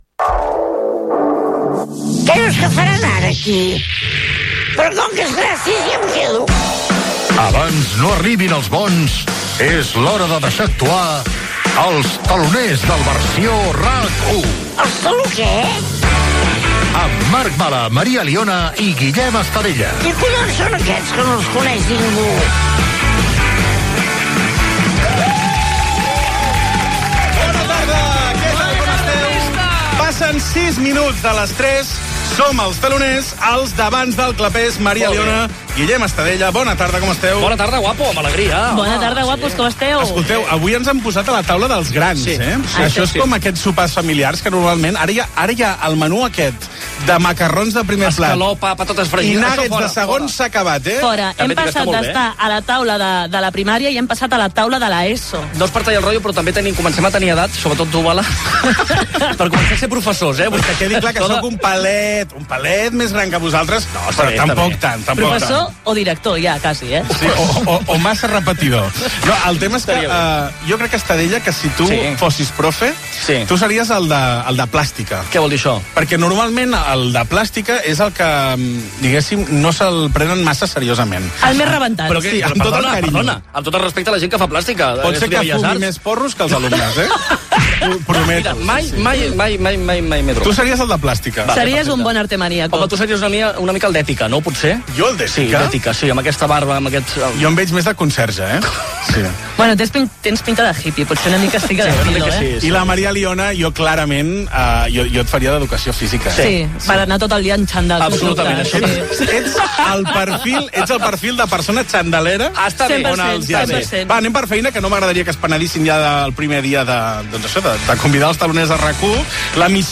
85c2db0da221b534f68299ceb4834e59c86fa8c9.mp3 Títol RAC 1 Emissora RAC 1 Barcelona Cadena RAC Titularitat Privada nacional Nom programa Els teloners del Versió RAC 1 Descripció Primera edició del programa. Careta del programa, salutació, diàleg inicial de l'equip, repàs de titulars d'actualitat
el cas de Daniel Sancho, lligar al Mercadona Gènere radiofònic Entreteniment